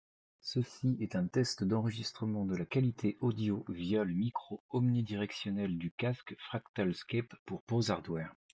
Với micro tích hợp và không có tiếng ồn môi trường, giọng nói bị bóp nghẹt hơn, kém rõ ràng hơn nhưng đủ để trả lời cuộc gọi điện thoại.
• [Mẫu âm thanh Micro tích hợp đa hướng]